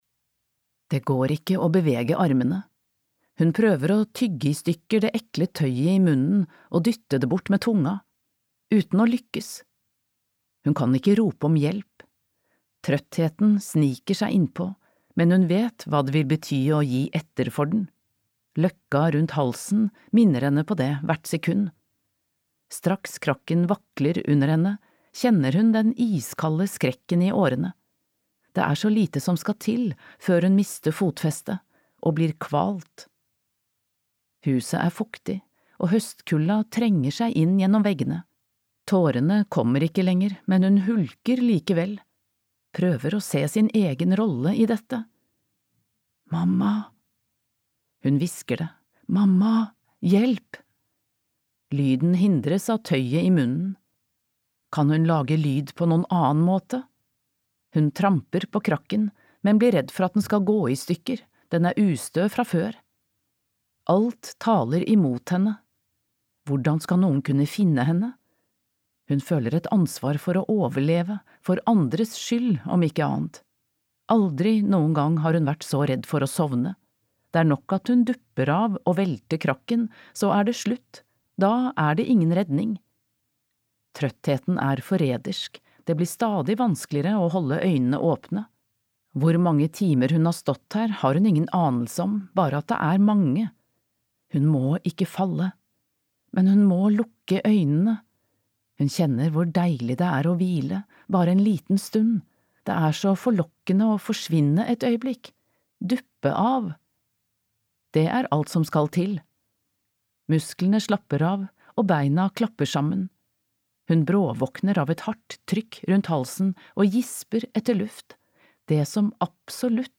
Skammekroken (lydbok) av Sofie Sarenbrant